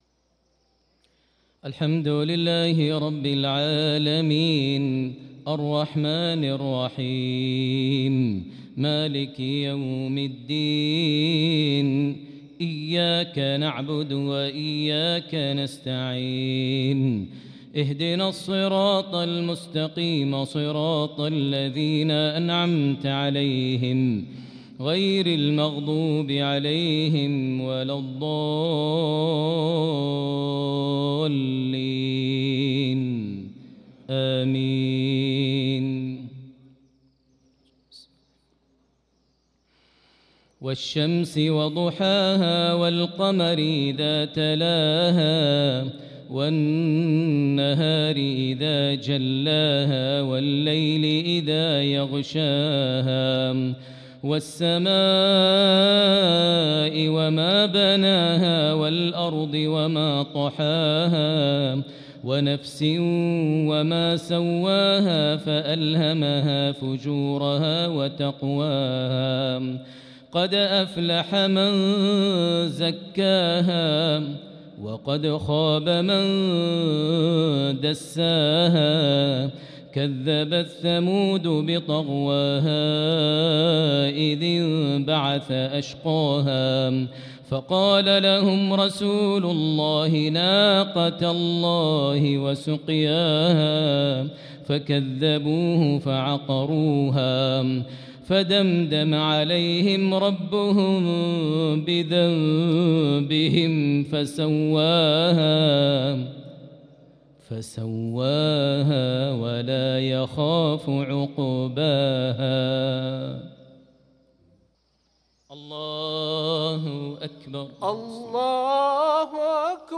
صلاة المغرب للقارئ ماهر المعيقلي 1 شعبان 1445 هـ
تِلَاوَات الْحَرَمَيْن .